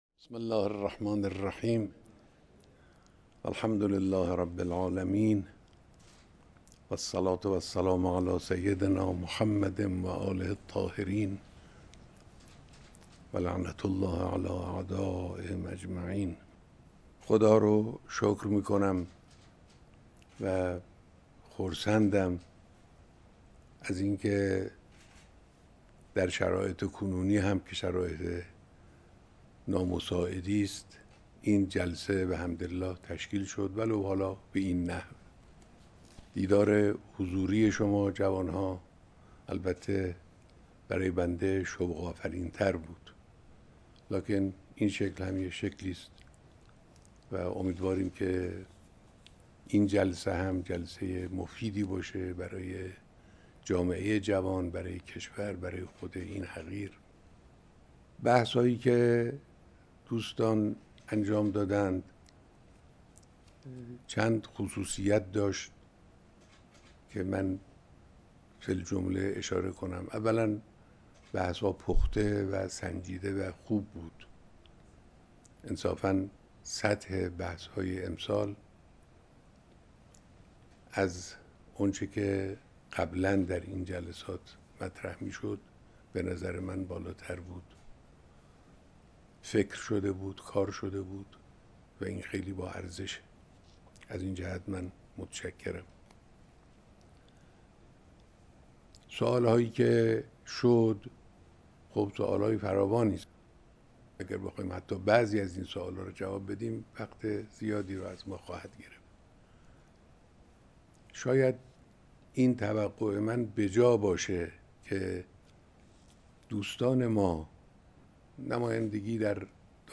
بیانات در ارتباط تصویری با نمایندگان تشکل‌های دانشجویی